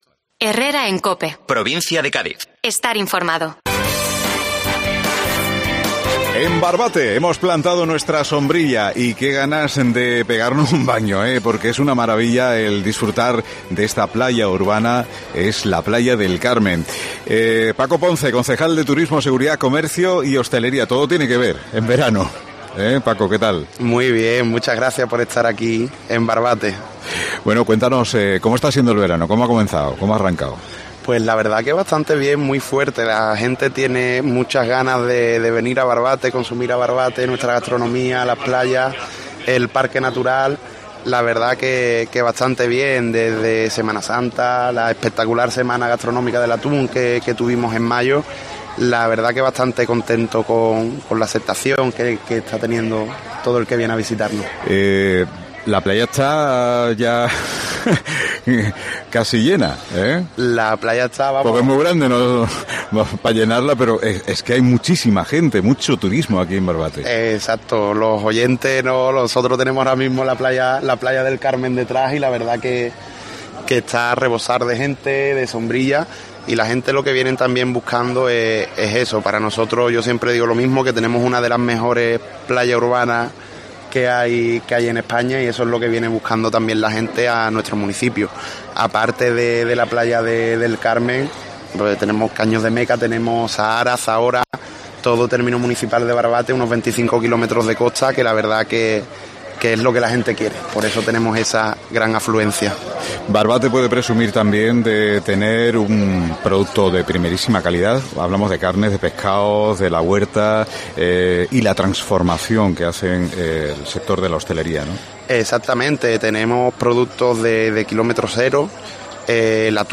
Entrevistamos a Paco Ponce, concejal de Turismo del Ayuntamiento de Barbate (Cádiz)